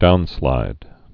(dounslīd)